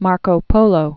(märkō pōlō)